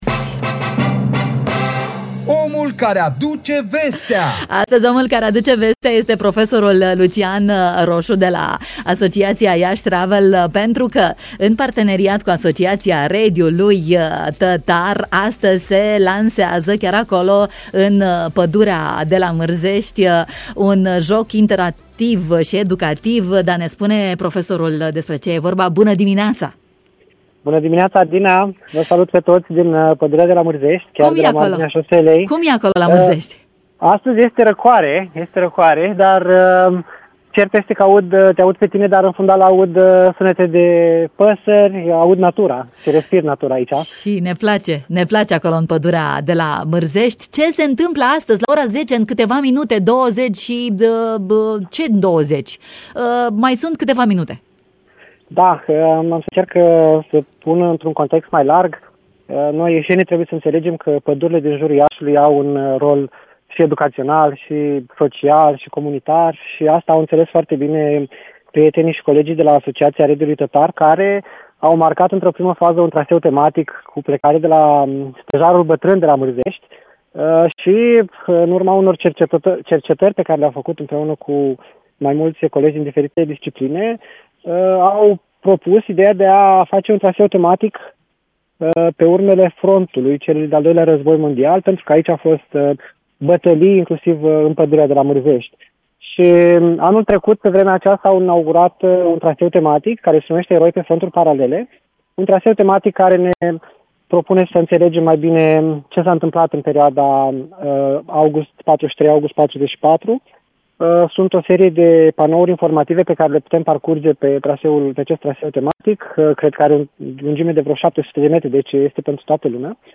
în matinalul de la Radio Iași